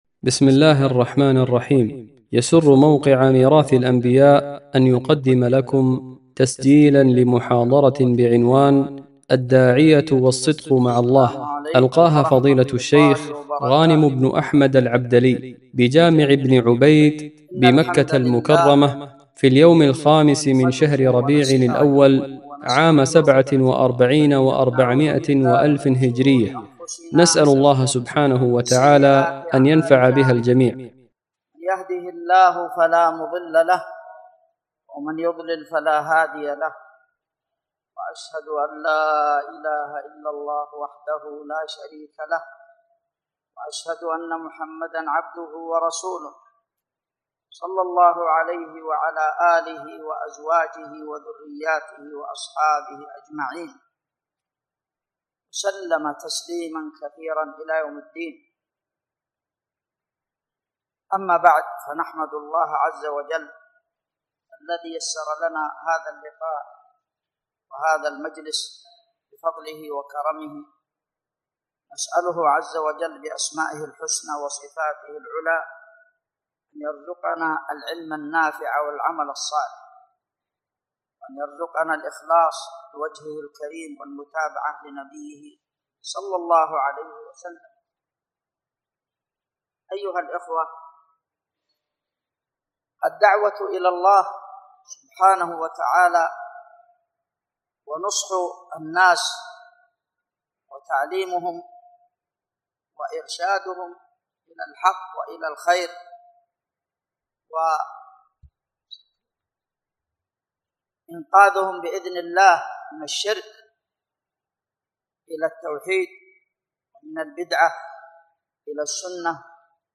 محاضرة
محاضرة-الداعية-والصدق-مع-الله-.mp3